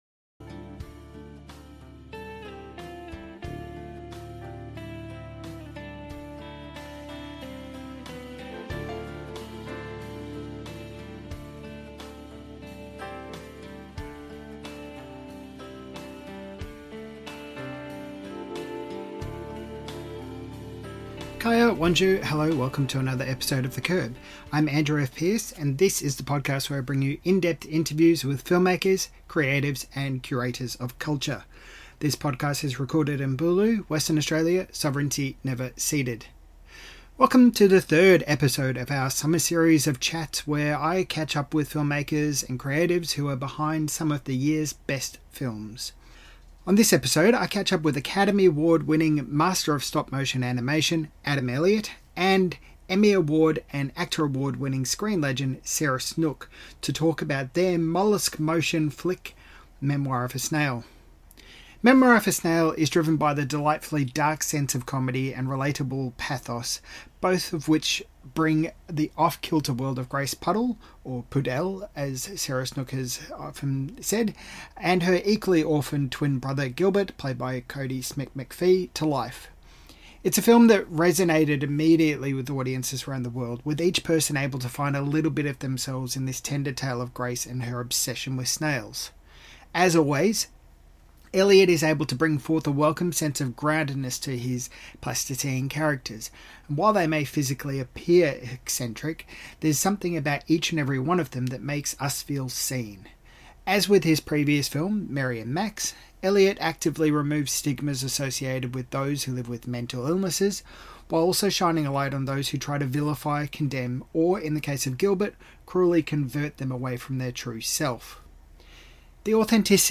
Adam Elliot & Sarah Snook Talk About Bringing Heart to their Mollusk-motion Flick Memoir of a Snail to Life - The Curb | Film and Culture